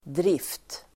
Uttal: [drif:t]